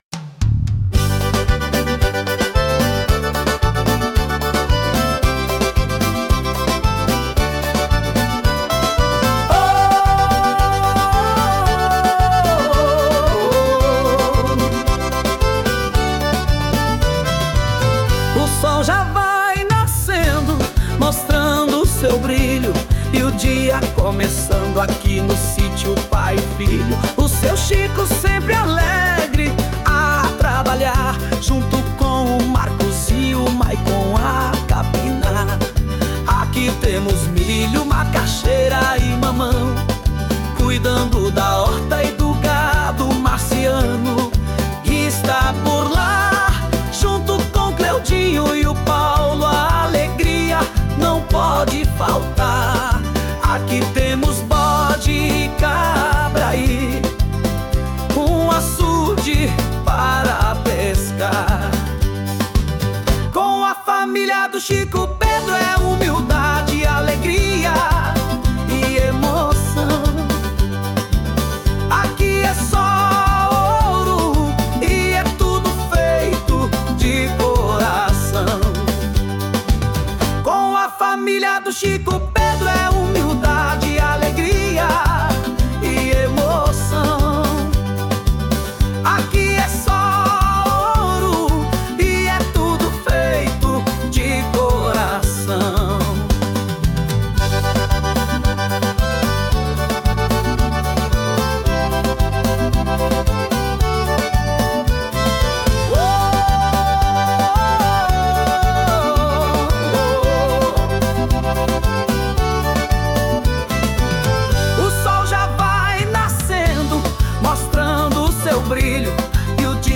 [Vocal Masculino]